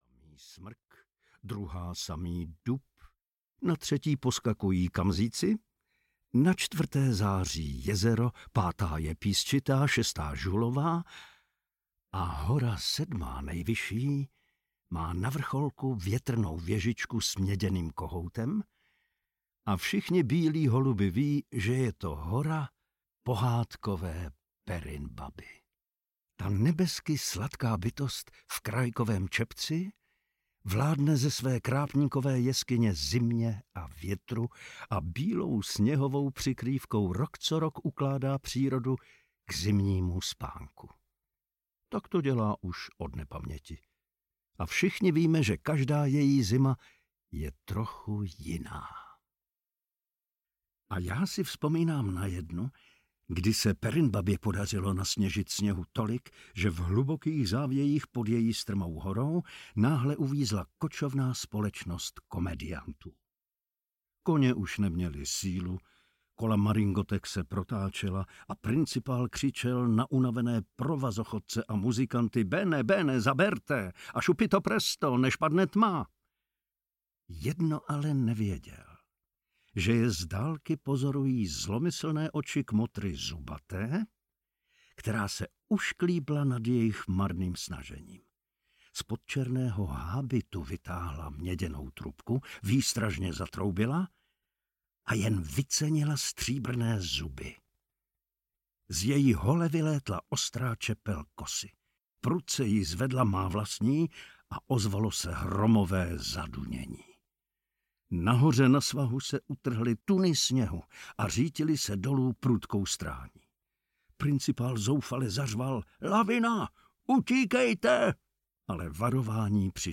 Perinbaba audiokniha
Ukázka z knihy